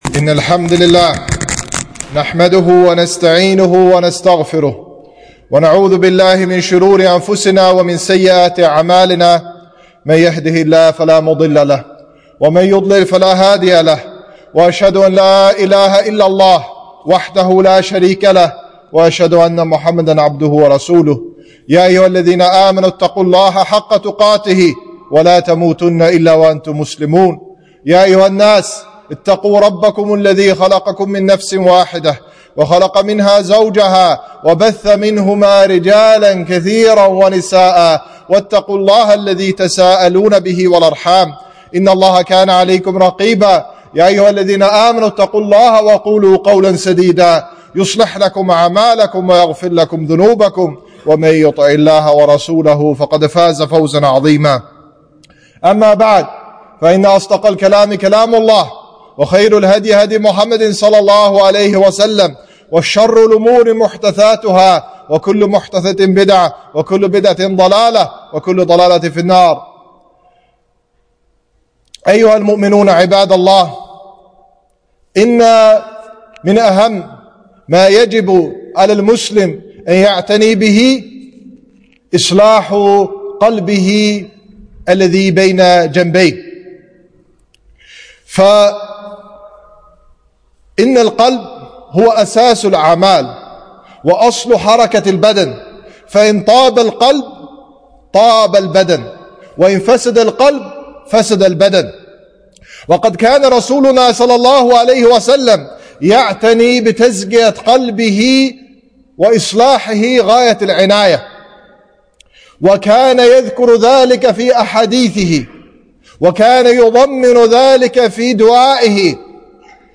خطبة - إصلاح القلوب ٢٦-رجب-١٤٤٤-هـ (الكويت)